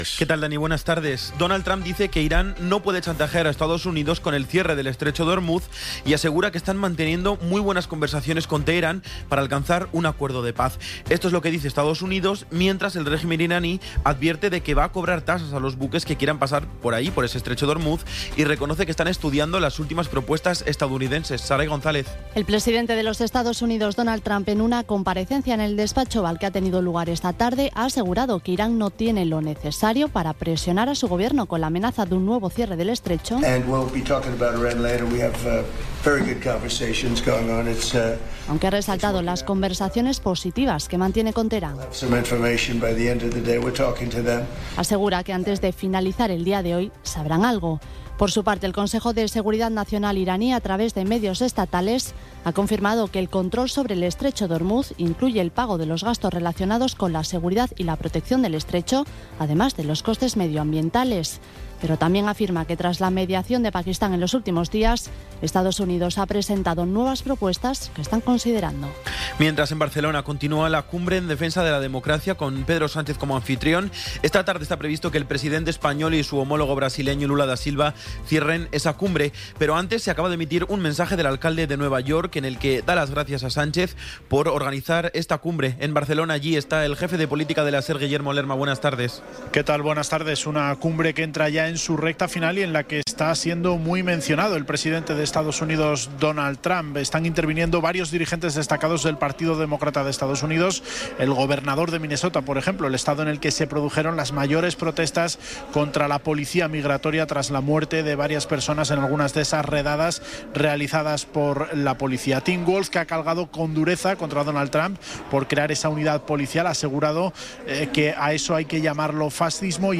Resumen informativo con las noticias más destacadas del 18 de abril de 2026 a las seis de la tarde.